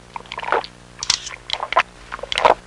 Drinking Sound Effect
Download a high-quality drinking sound effect.
drinking.mp3